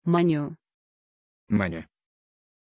Pronunciation of Monya
pronunciation-monya-ru.mp3